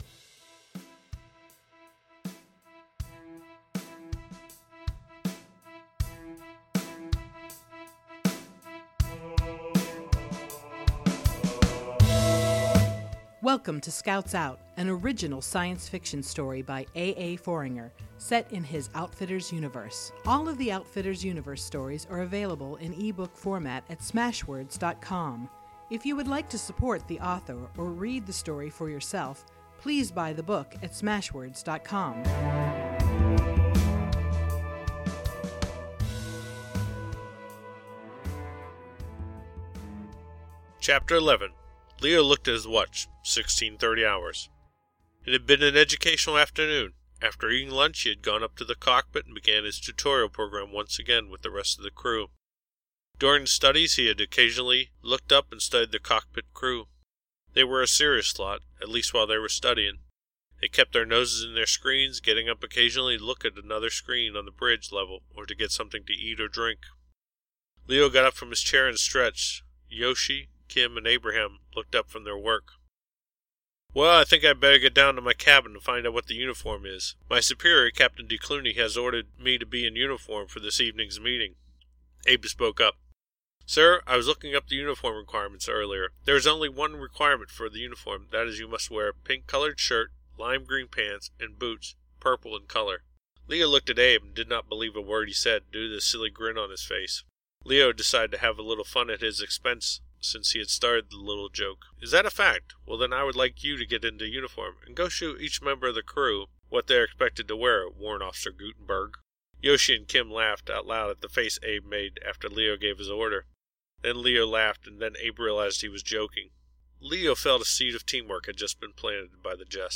So if you ever wanted to know what my voice sounds like, it will be me reading the book.